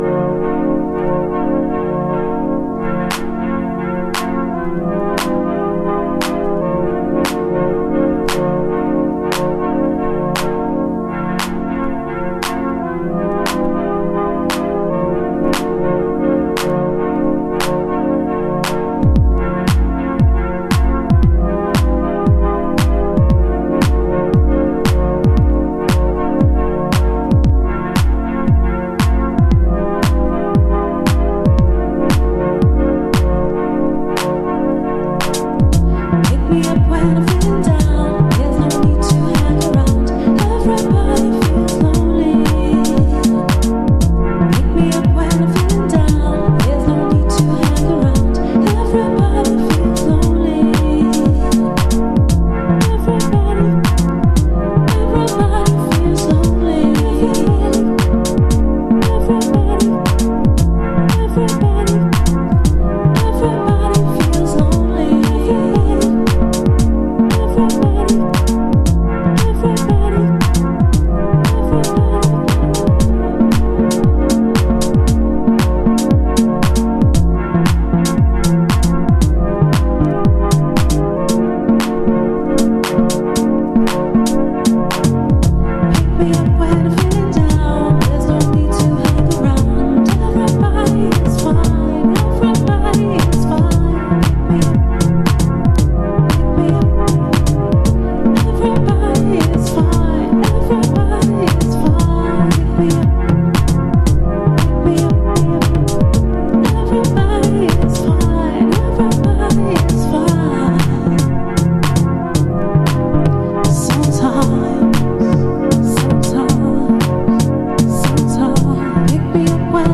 エクスタティックな空間系シンセが揺らめく4トラック。